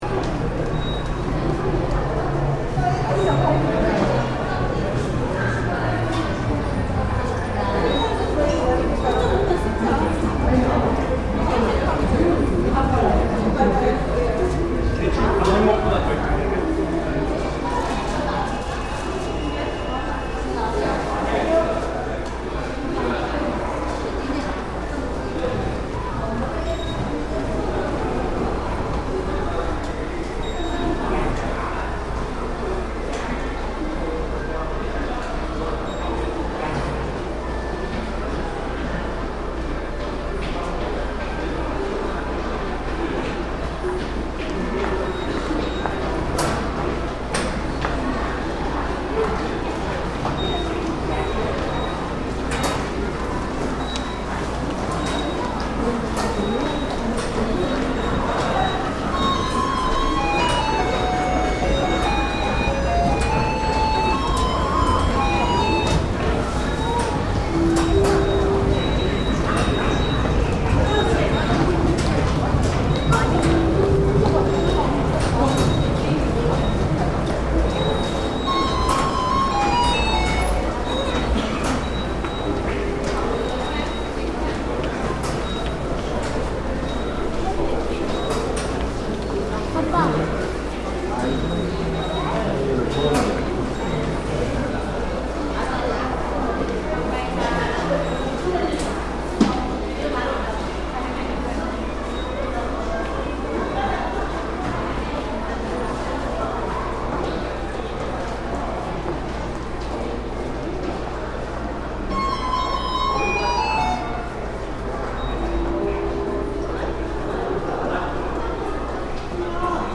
Two trains at once in Seoul
The greater Seoul metropolitan area is crisscrossed by an efficient metro system with 23 lines stopping at 728 stations. Clear signage is accompanied by audible indications in multiple languages.
At Hapjeong Station, riders from two trains disembark at the same time, exiting through turnstiles on either side of the station passageway.